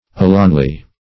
Search Result for " alonely" : The Collaborative International Dictionary of English v.0.48: Alonely \A*lone"ly\, adv. Only; merely; singly.